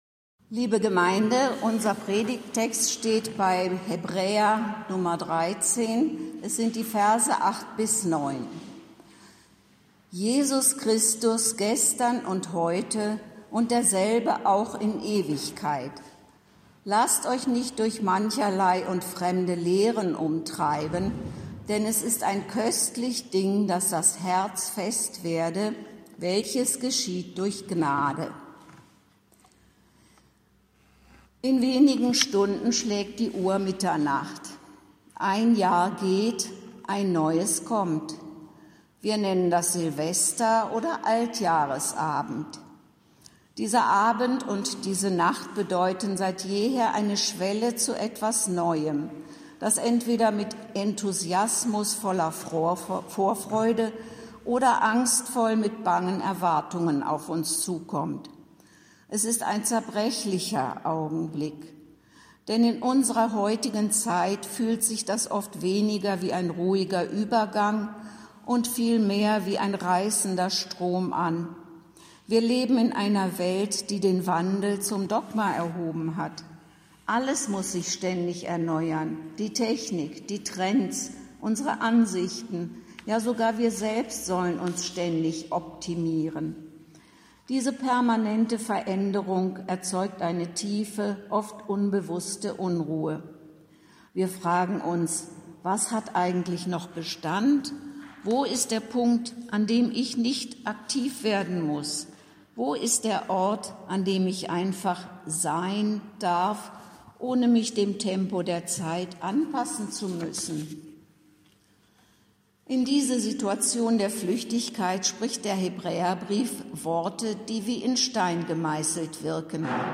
Predigt zum Altjahrsabend